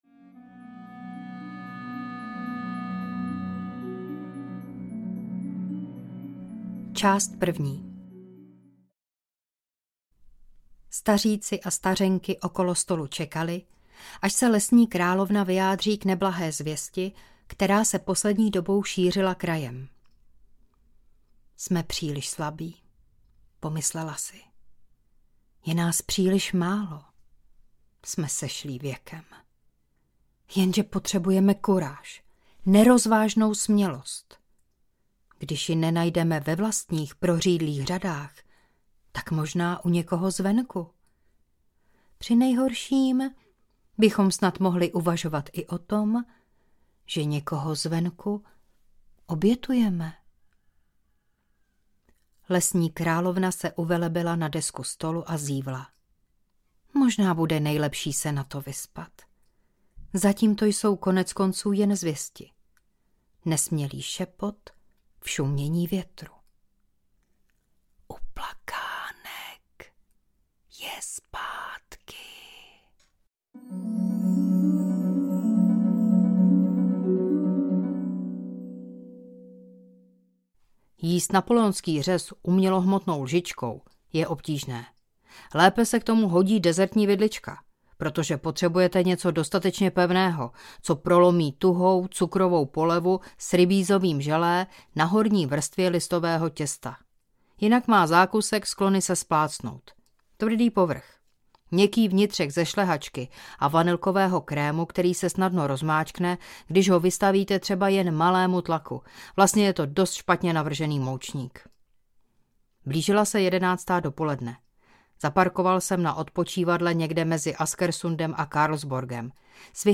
Kouzelníkův únik z reality audiokniha
Ukázka z knihy
kouzelnikuv-unik-z-reality-audiokniha